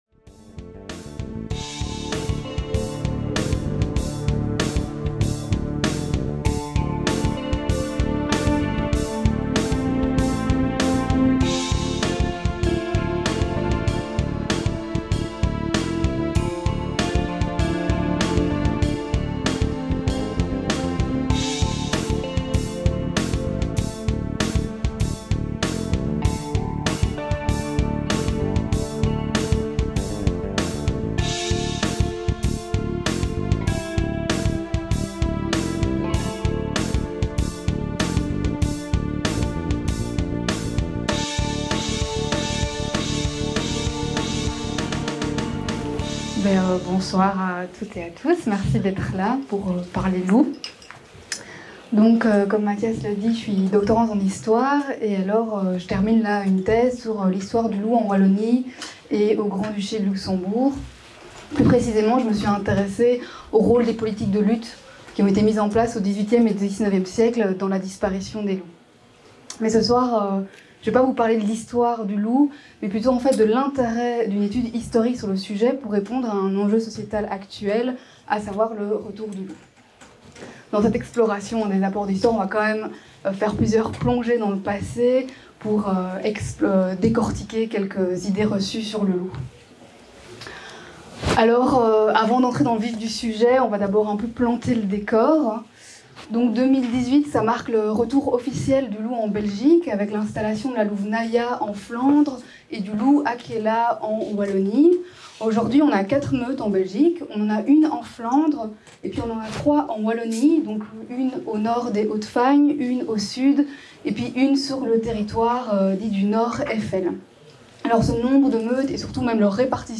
Cette conférence a été donnée le 22 février 2025 dans le cadre des Skeptics in the Pub Bruxelles, un cycle de conférences organisé par le Comité Para asbl.